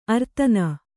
♪ artana